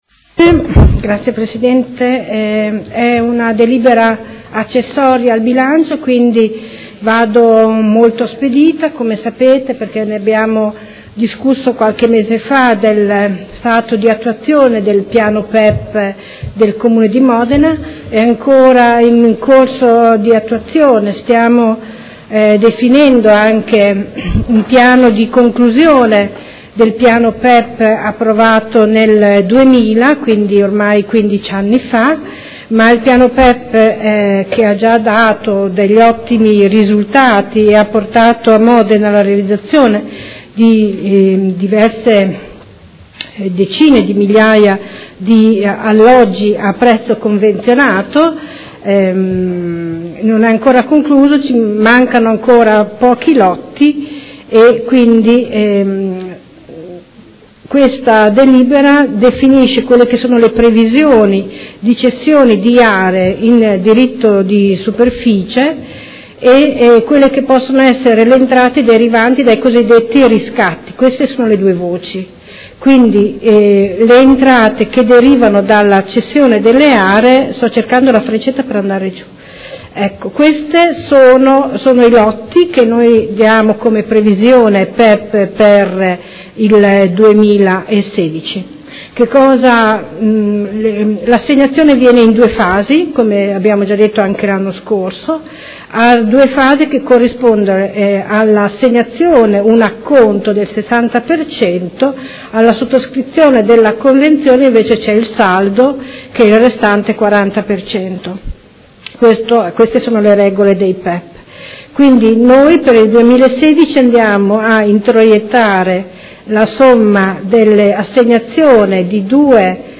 Anna Maria Vandelli — Sito Audio Consiglio Comunale
Seduta del 28 gennaio.